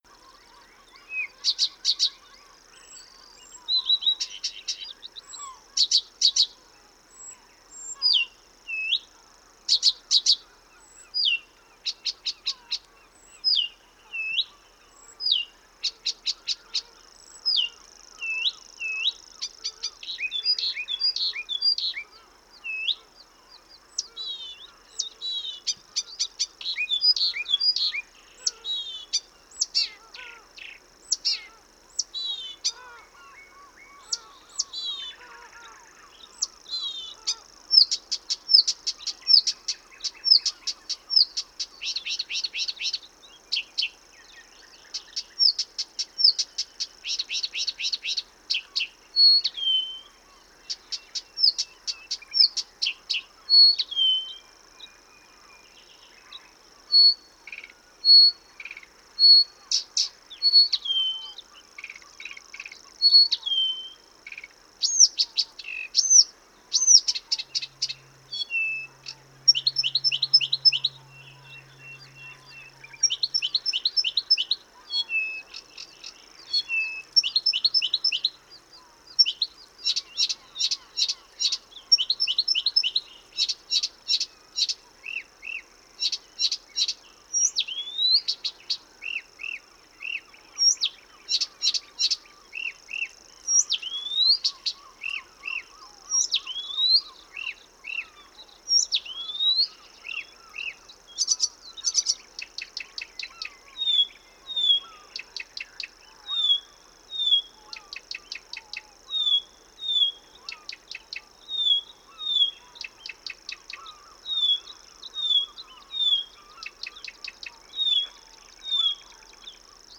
Struikrietzanger
zingt fantastisch mooi – ook ’s nachts! – en is bovendien een zeldzame vogel in Nederland.
Struikrietzanger op Texel